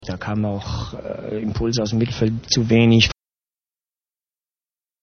HIAT: 6.3 Dehnung [Beispiel 84-86]